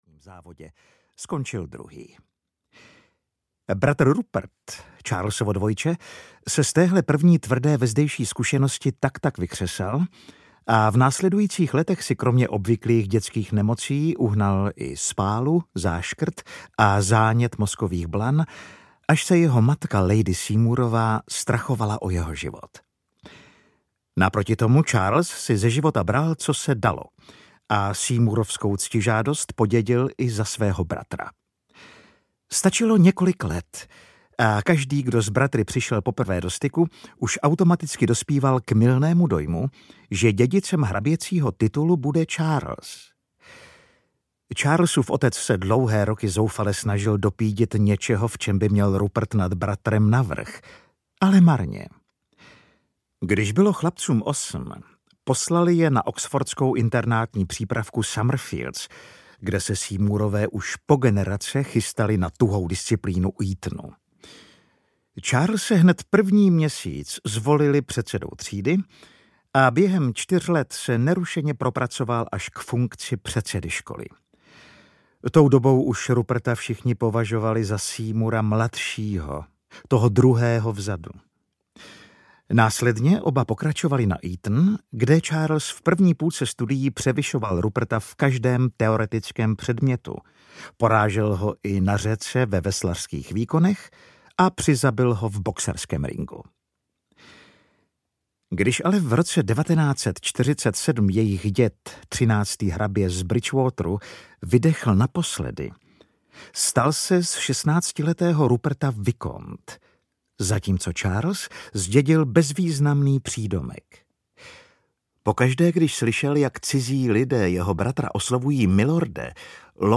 První mezi rovnými audiokniha
Ukázka z knihy
• InterpretAleš Procházka